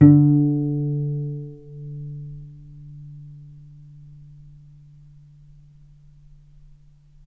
healing-soundscapes/Sound Banks/HSS_OP_Pack/Strings/cello/pizz/vc_pz-D3-mf.AIF at ae2f2fe41e2fc4dd57af0702df0fa403f34382e7
vc_pz-D3-mf.AIF